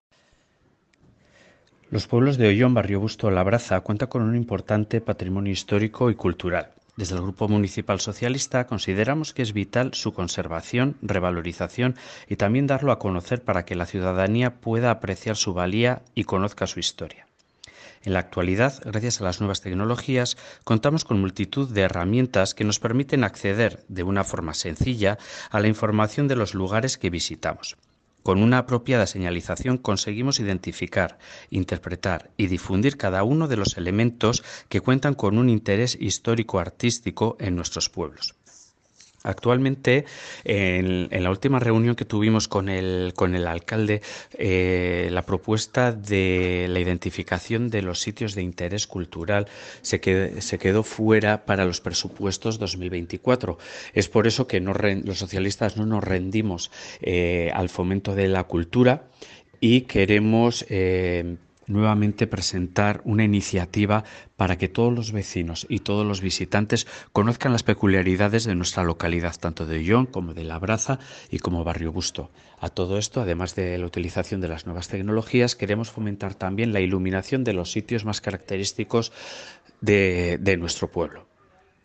“A través de un itinerario señalizado y con una iluminación que destaque los elementos de interés, «queremos que nuestros vecinos, vecinas y visitantes puedan conocer la historia de una forma ordenada explicaba en el pleno celebrado ayer por la noche el concejal socialista en el Ayuntamiento de Oion, Oscar Layana.